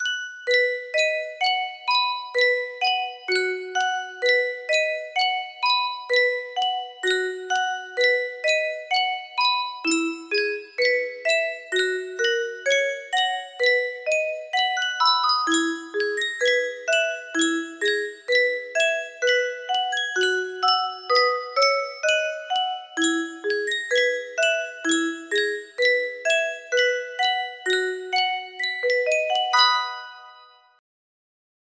Yankee Doodle music box melody
Full range 60